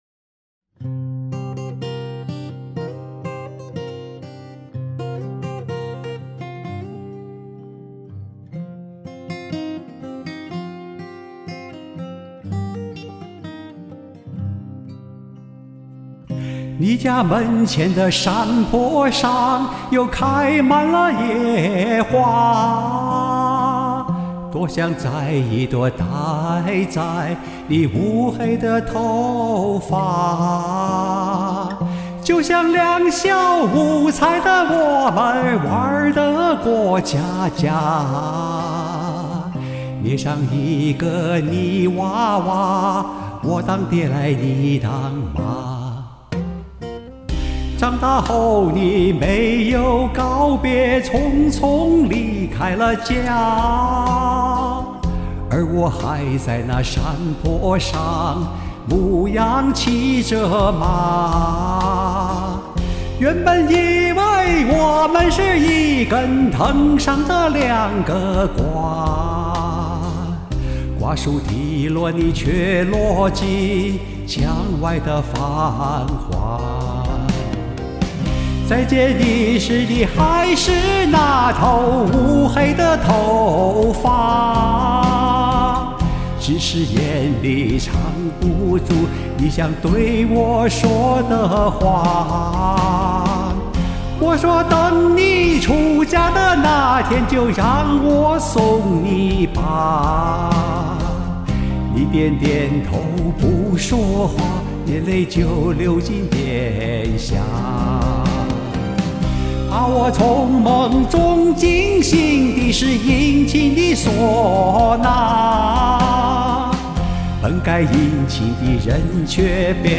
又一首网红歌曲，精彩深情的演唱，赞！
中间的那个萨克斯过度，心碎一地。